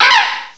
cry_not_rufflet.aif